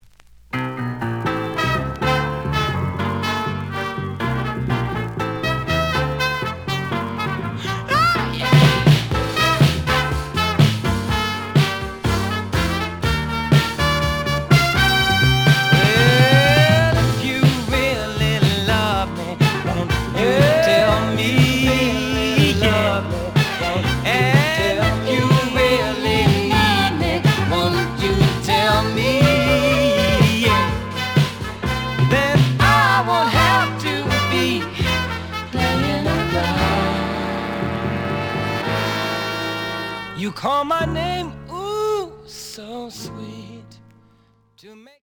●Genre: Soul, 70's Soul